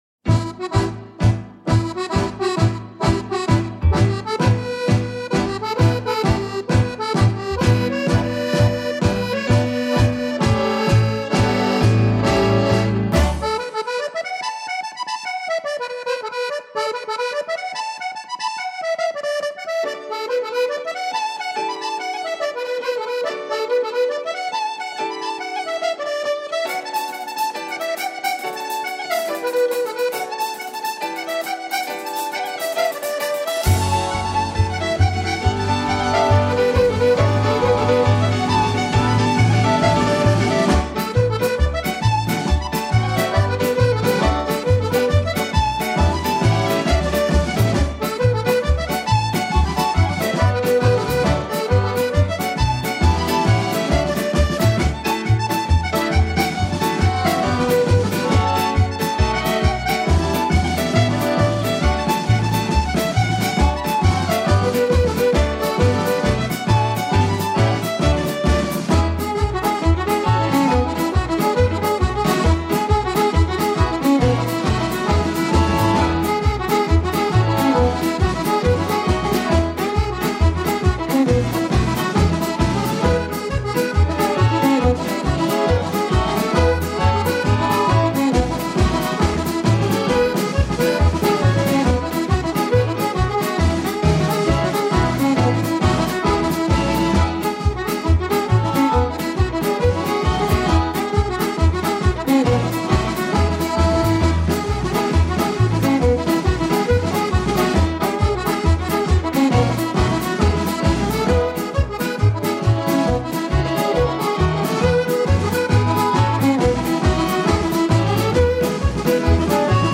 Genre: Traditional Country.